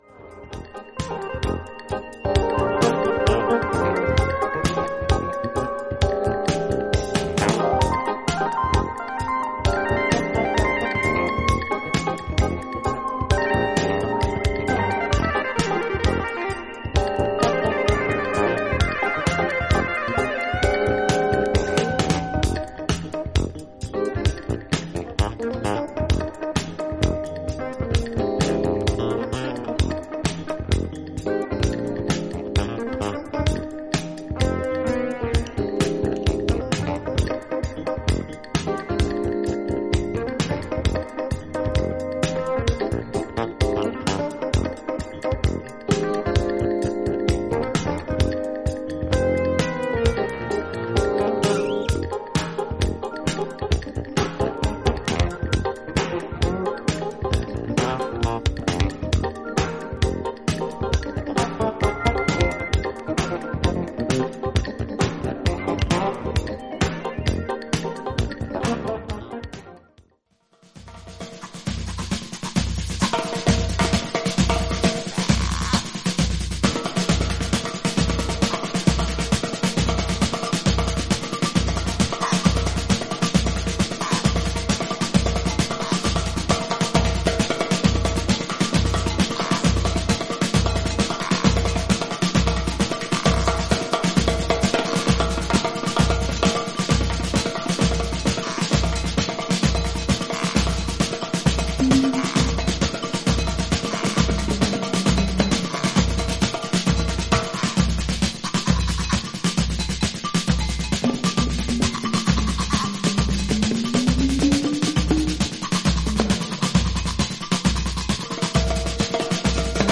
ファンキーなドラム&ベースと、エレピの絡みが印象的なブラジリアン・フュージョン
グルーヴィーなバトゥカーダ・ナンバー